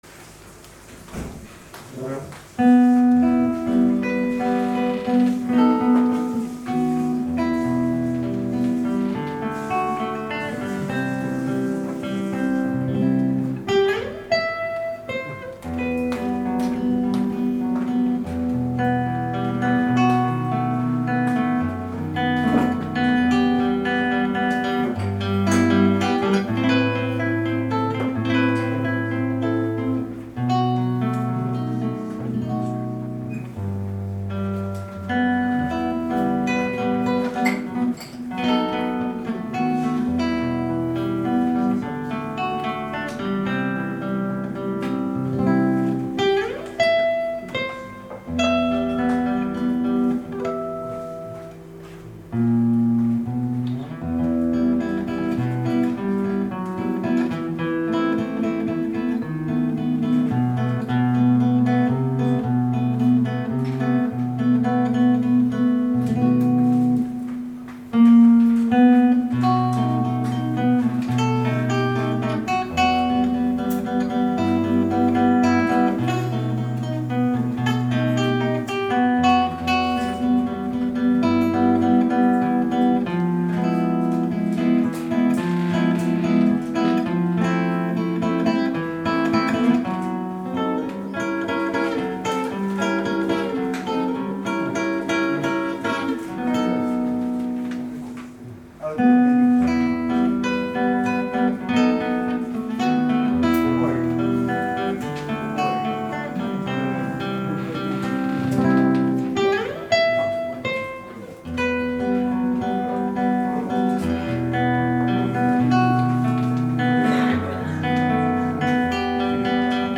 偕行社文化祭２０１９